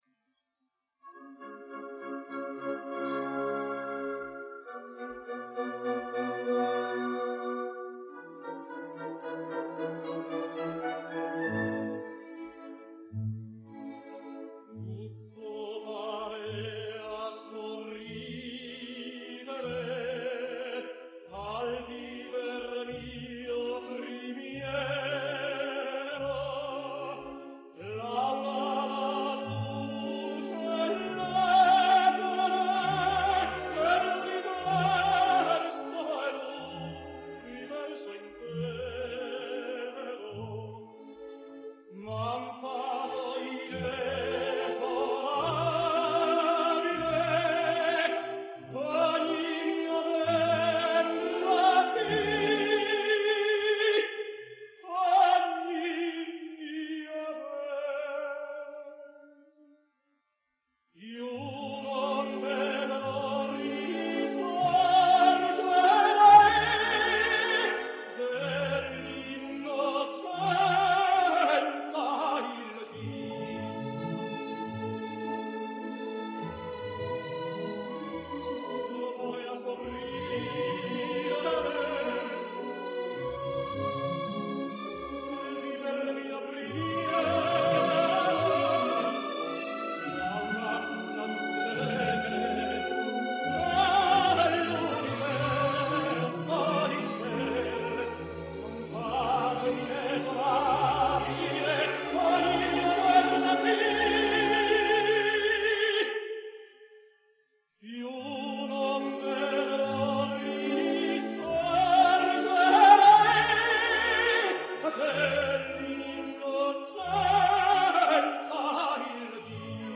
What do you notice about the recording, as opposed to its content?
live recordings